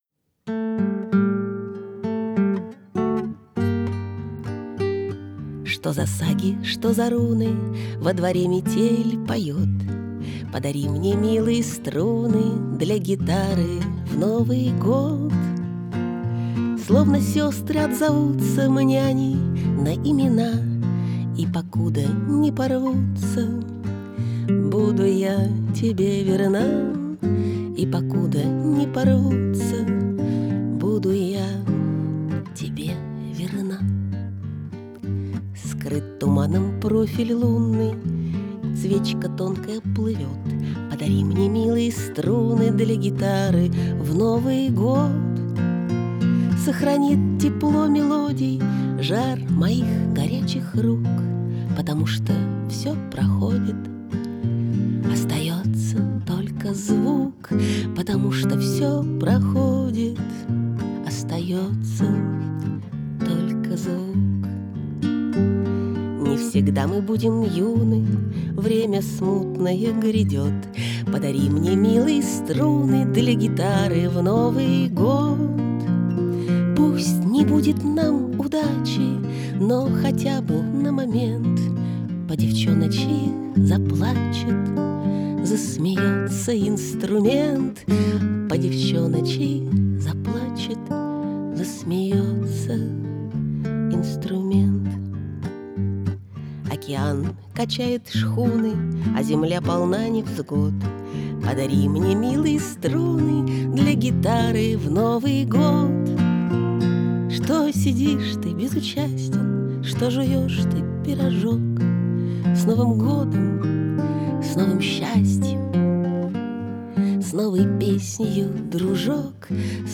Состоялся очередной, 71-й вечер проекта "Споём вместе!", целиком посвящённый песням Александра Городницкого.
Так, ведь, там гитара, видимо, не её...